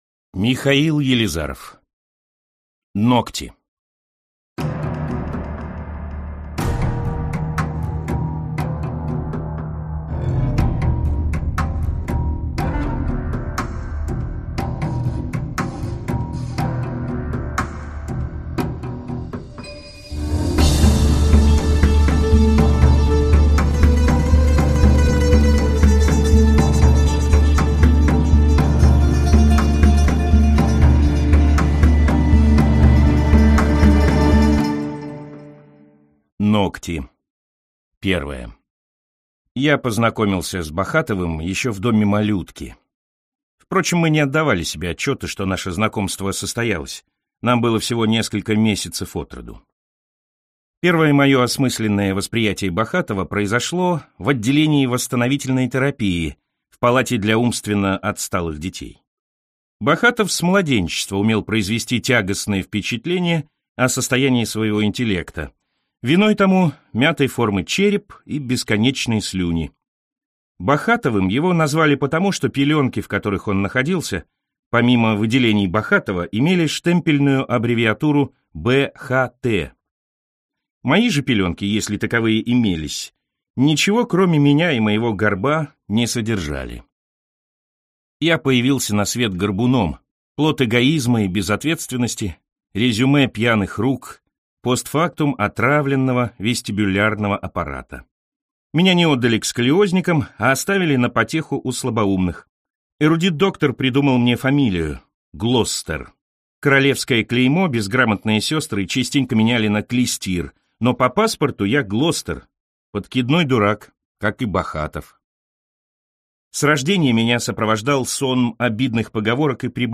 Аудиокнига Ногти | Библиотека аудиокниг